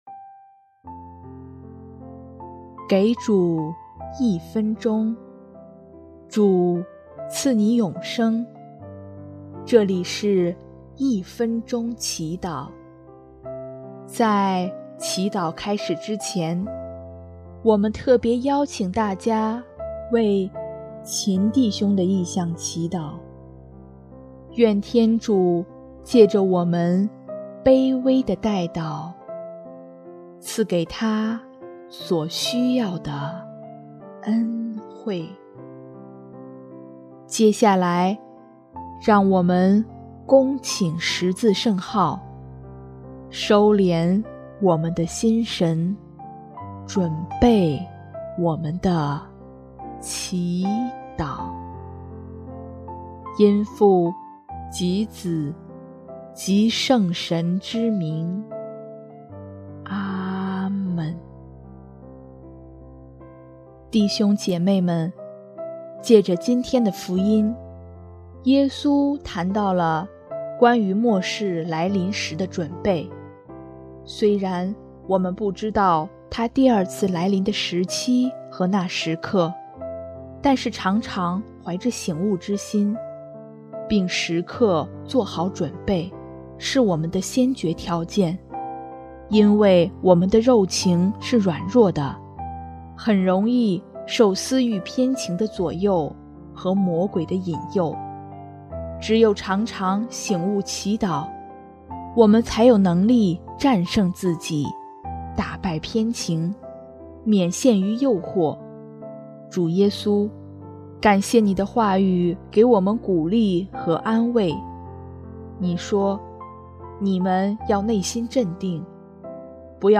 音乐：主日赞歌《悔悟》